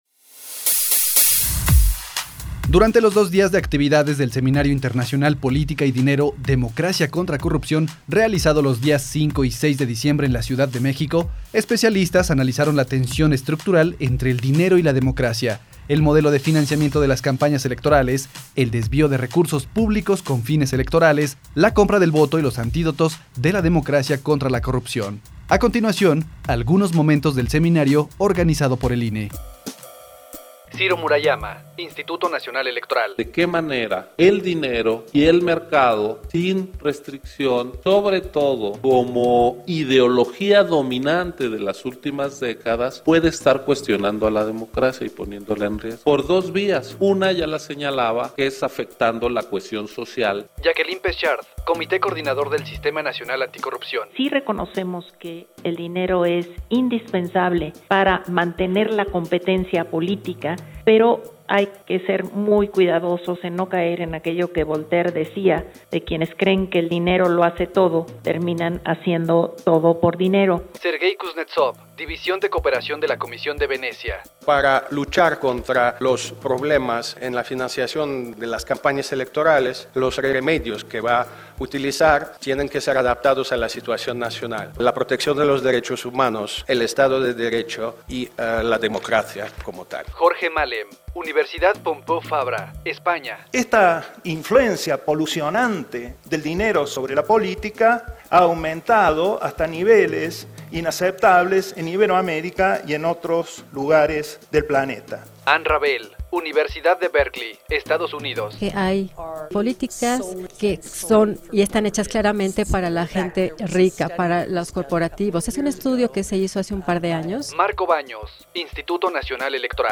Especialistas analizaron la relación entre el dinero y la política en seminario internacional